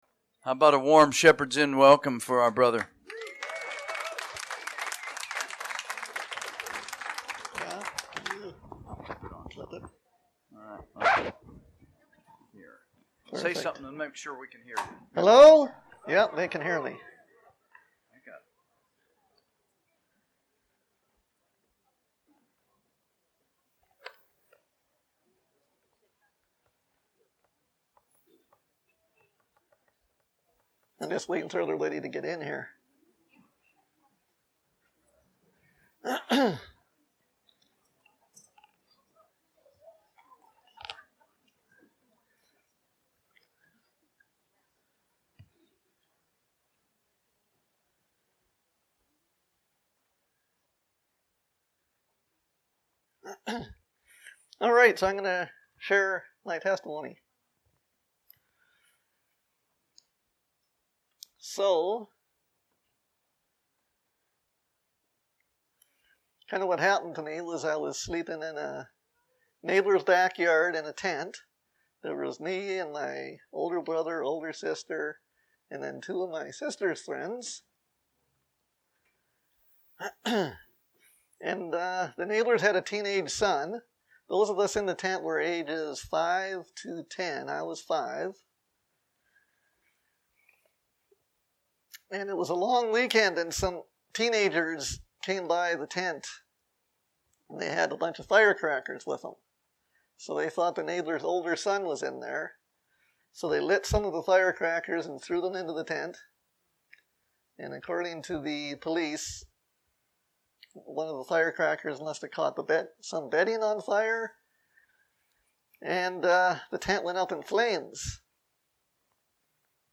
Shepherds Christian Convention , Teachings , Visiting Speakers